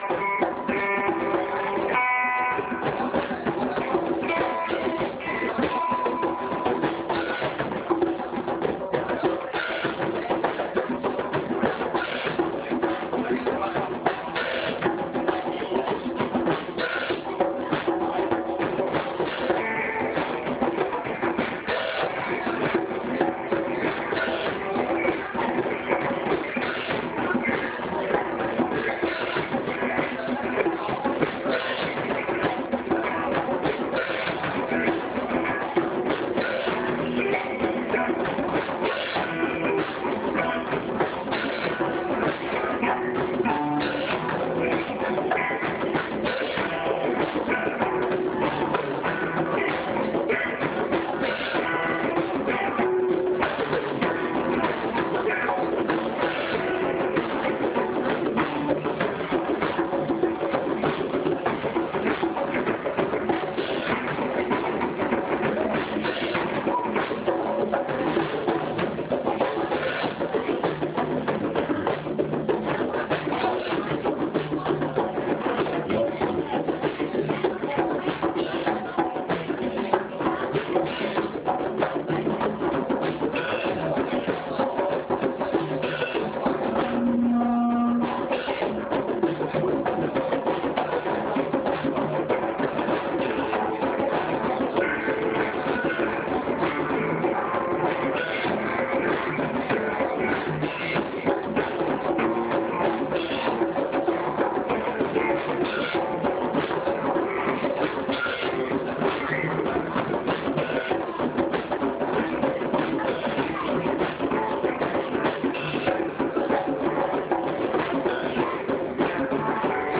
Another jam with a wall of djembes in the houses of drumming.wav
Falls Creek R&R - another jam with a wall of djembes in the houses of drumming.wav